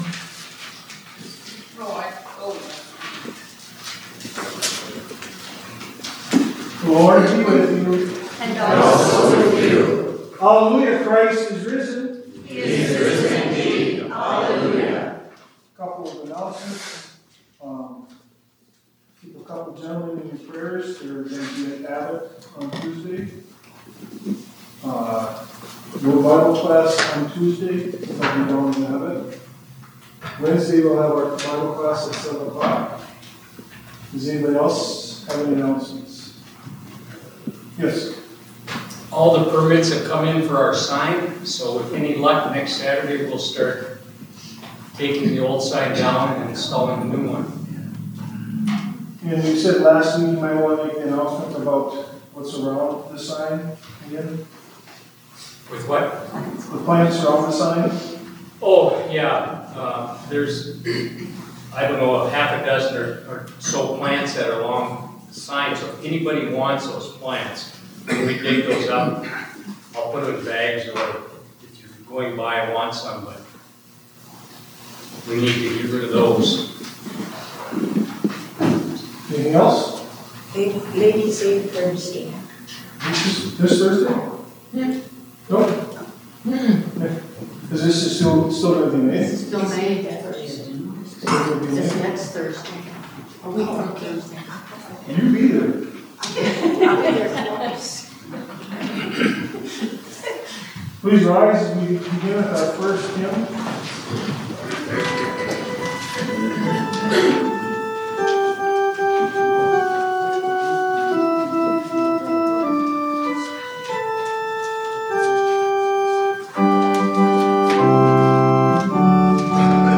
Zion Worship 25 May 25